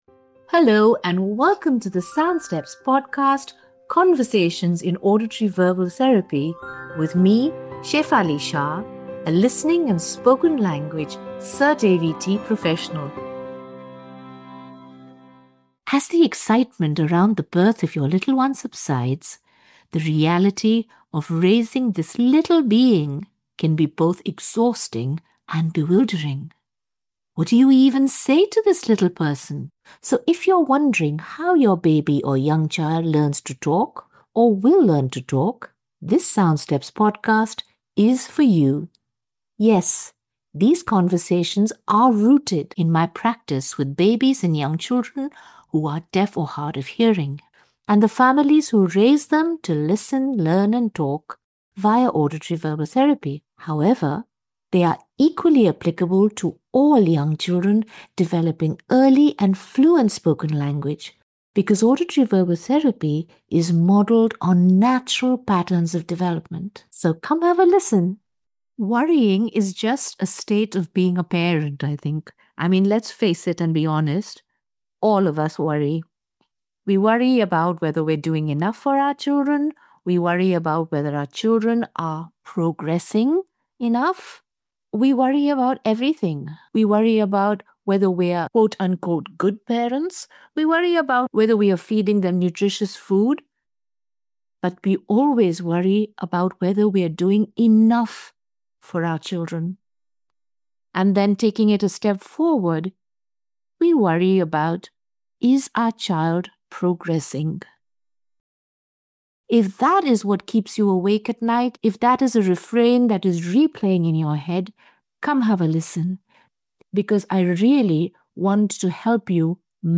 honest, penetrating conversation on how to plan for progress